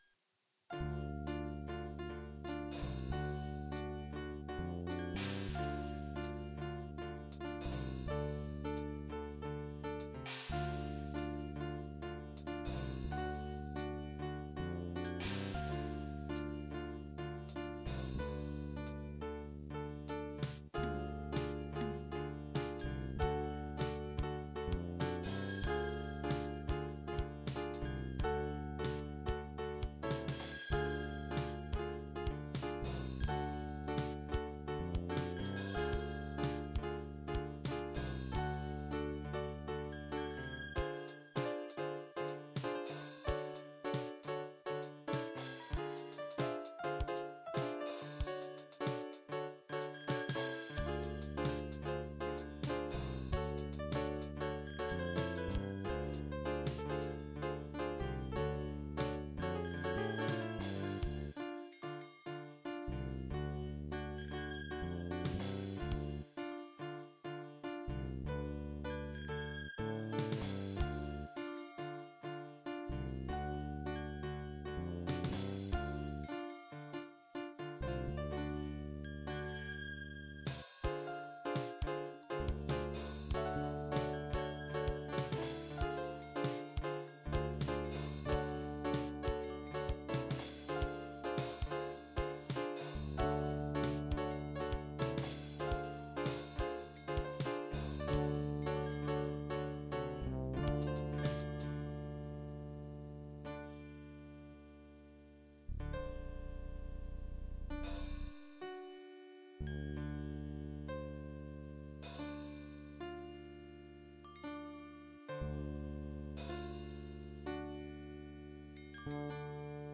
Piano Chords Bells, and Fretless bass